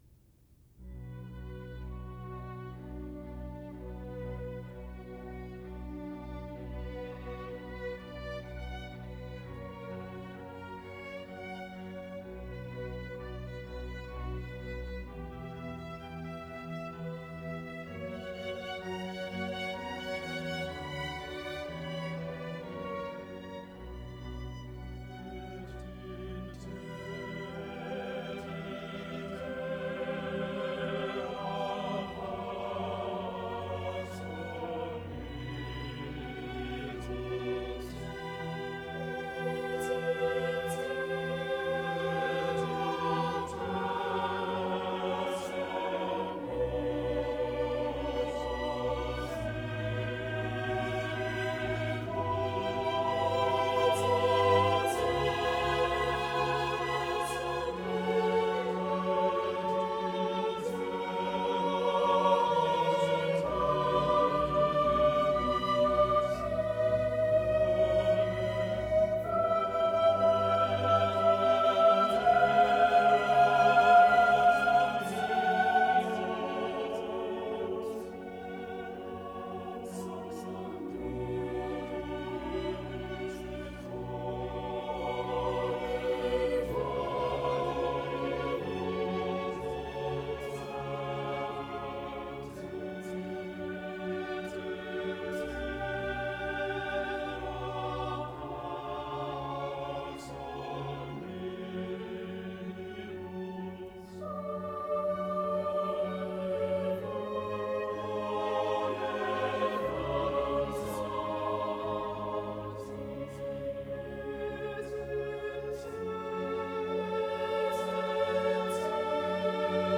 performed by the Choir of King's College